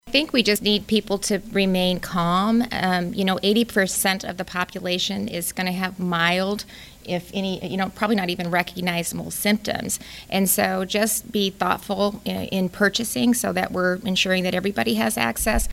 GOVERNOR KIM REYNOLDS SAYS PEOPLE NEED TO CALM DOWN: